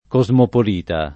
vai all'elenco alfabetico delle voci ingrandisci il carattere 100% rimpicciolisci il carattere stampa invia tramite posta elettronica codividi su Facebook cosmopolita [ ko @ mopol & ta ; non -p 0 - ] s. m. e f. e agg.; pl. m. -ti